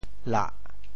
潮州府城POJ lah 国际音标 [la?]
lah4.mp3